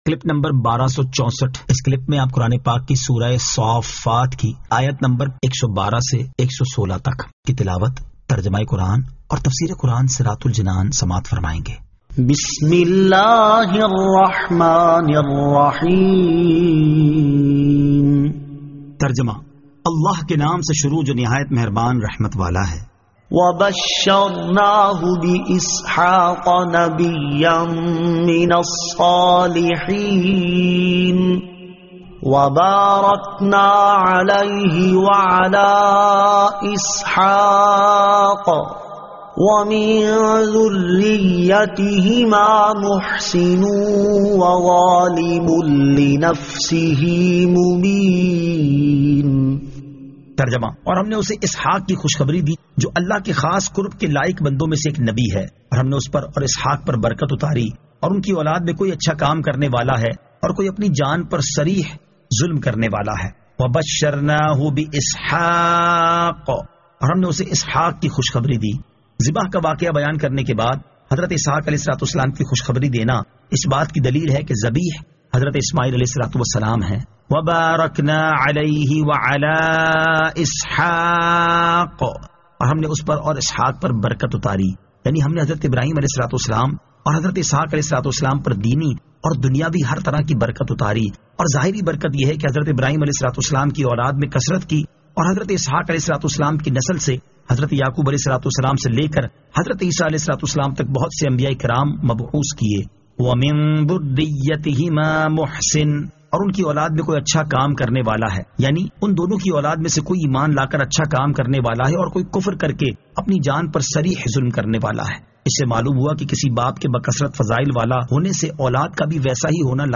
Surah As-Saaffat 112 To 116 Tilawat , Tarjama , Tafseer